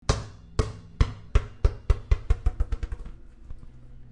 r1-chaos-bouncing_ball.mp3